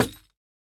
Minecraft Version Minecraft Version 1.21.5 Latest Release | Latest Snapshot 1.21.5 / assets / minecraft / sounds / block / bamboo_wood_hanging_sign / break1.ogg Compare With Compare With Latest Release | Latest Snapshot